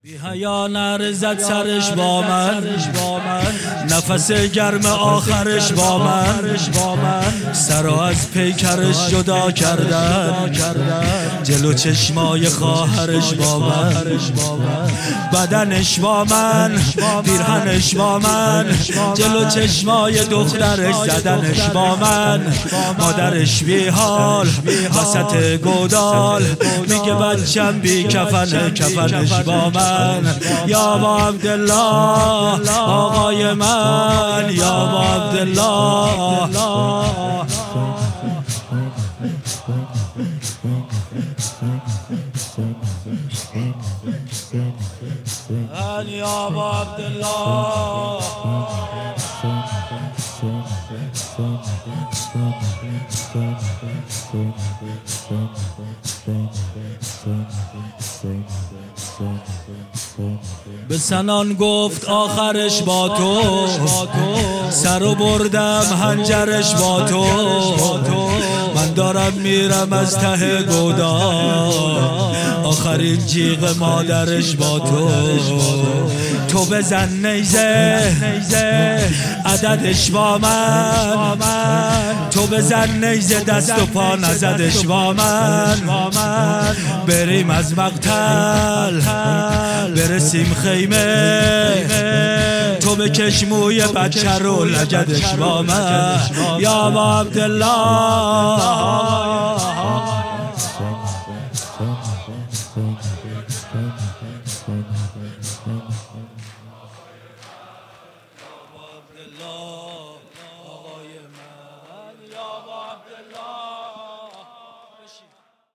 شور_بی حیا نعره زد سرش بامن